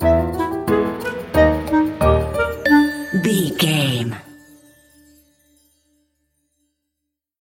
Aeolian/Minor
Slow
flute
oboe
piano
percussion
silly
circus
goofy
comical
cheerful
perky
Light hearted
quirky